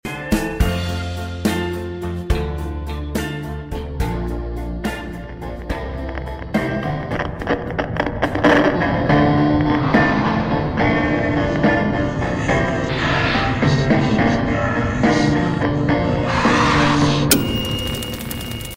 BMW M6 Sound Effects Free Download